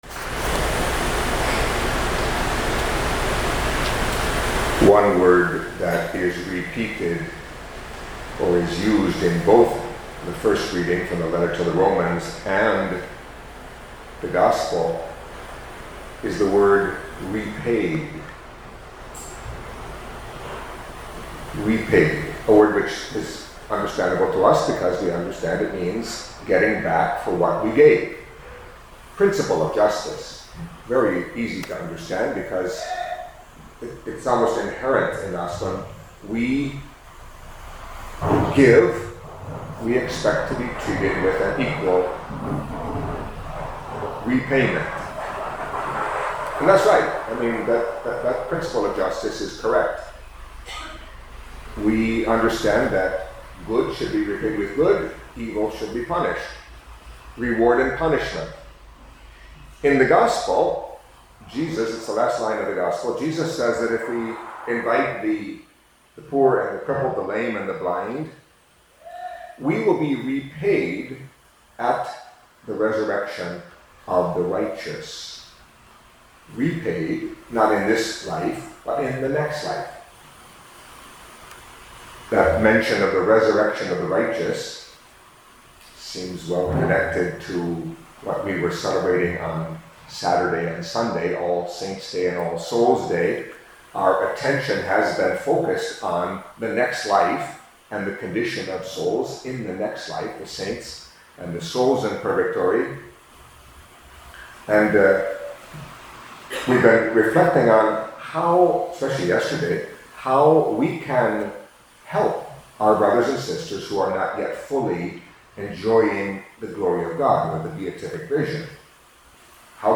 Catholic Mass homily for Monday of the Thirty-First Week in Ordinary Time